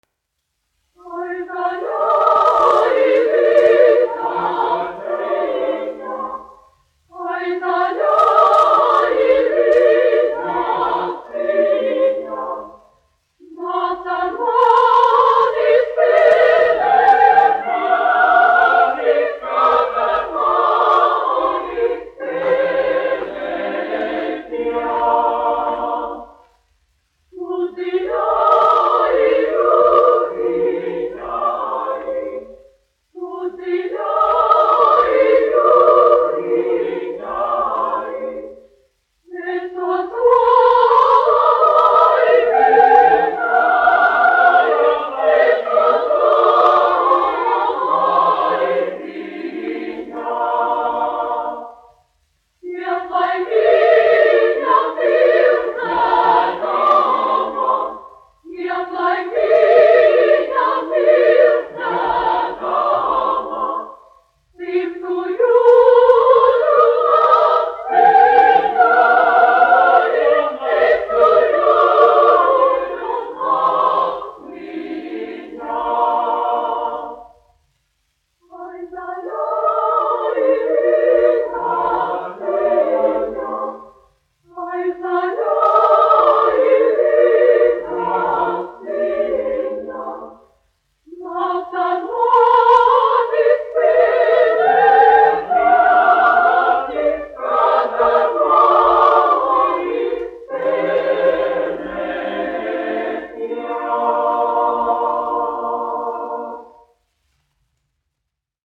Emilis Melngailis, 1874-1954, aranžētājs
Kalniņš, Teodors, 1890-1962, diriģents
Latvijas Radio koris, izpildītājs
1 skpl. : analogs, 78 apgr/min, mono ; 25 cm
Latviešu tautasdziesmas
Latvijas vēsturiskie šellaka skaņuplašu ieraksti (Kolekcija)